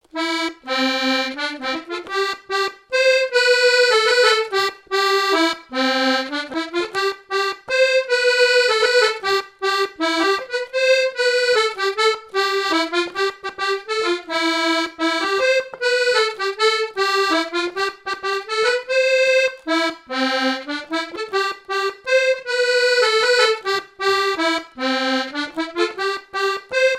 danse : scottish
Répertoire à l'accordéon diatonique
Pièce musicale inédite